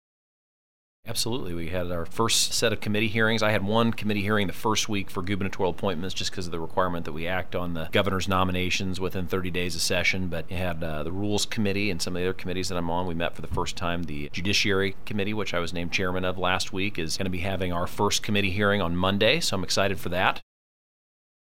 2. Senator Luetkemeyer adds he also sponsored a gubernatorial appointee this week.